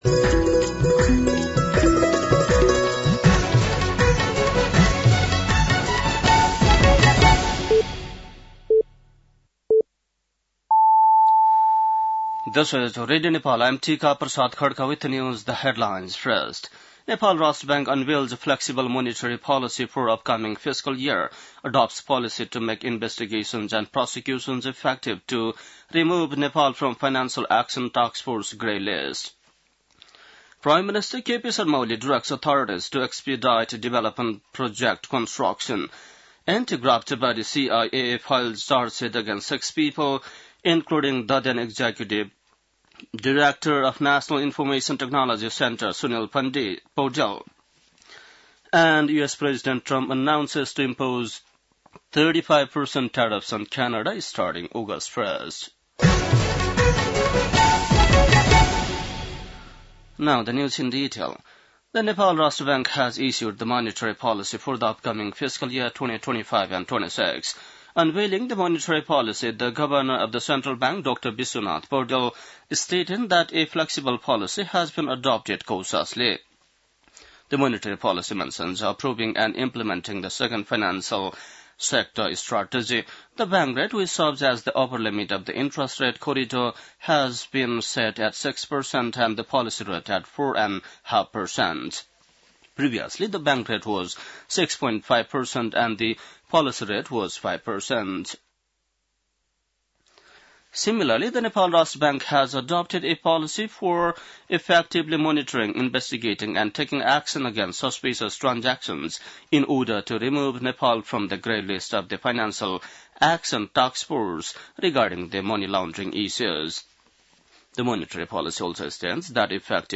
बेलुकी ८ बजेको अङ्ग्रेजी समाचार : २७ असार , २०८२
8-PM-English-NEWS-3-27.mp3